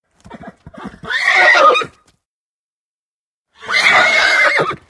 Звуки жеребца
Жеребец вскрикивает громко